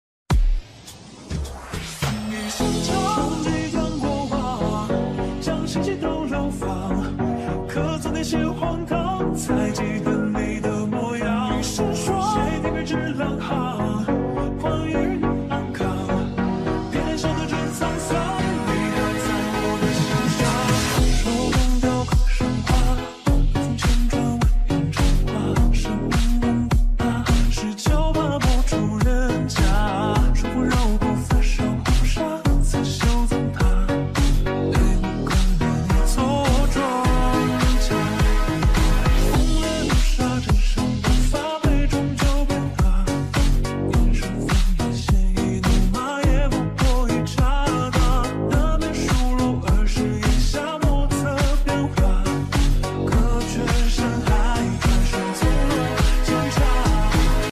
(slowed 0.8X)
(DJ抖音版 2024)